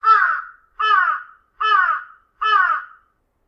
cuervo1
crow1.mp3